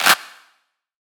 SOUTHSIDE_clap_flamourous.wav